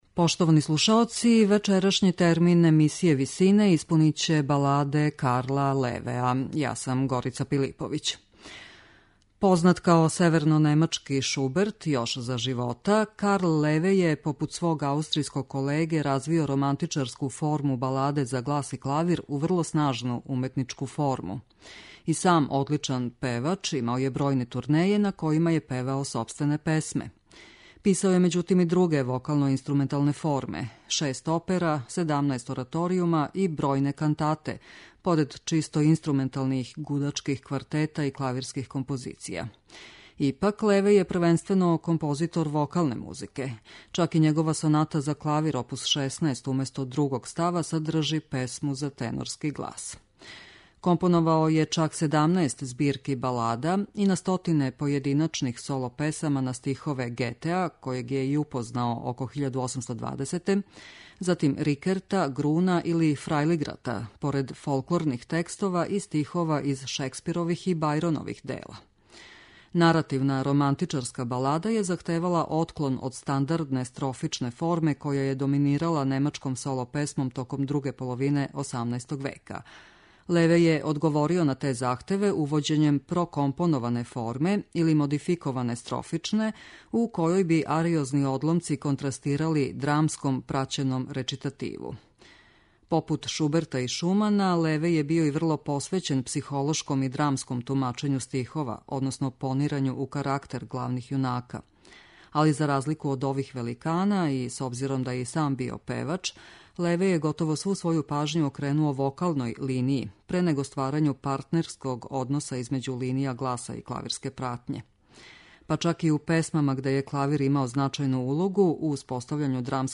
Карл Леве: Соло песме
медитативне и духовне композиције
Познат као севернонемачки Шуберт, Карл Леве је развио форму баладе за глас и клавир у врло снажну уметничку форму.